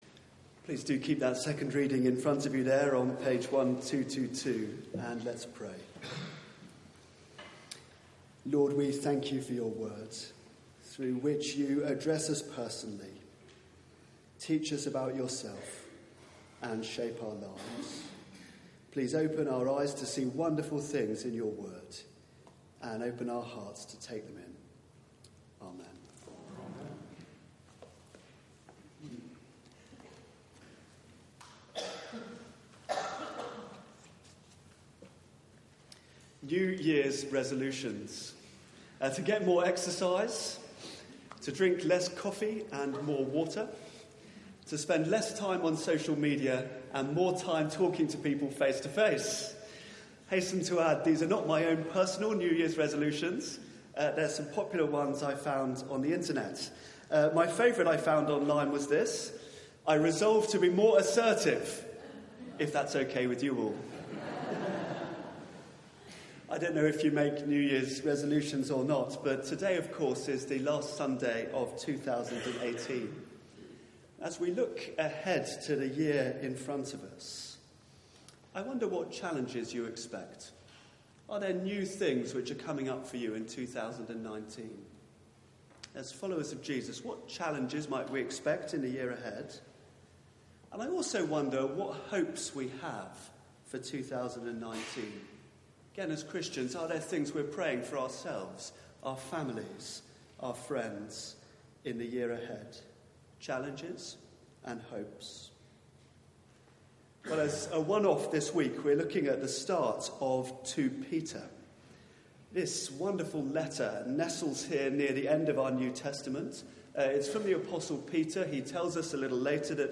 Media for 9:15am Service on Sun 30th Dec 2018 09:15 Speaker
Sermon